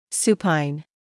[‘s(j)uːpaɪn][‘с(й)уːпайн]лежащий на спине; супинированный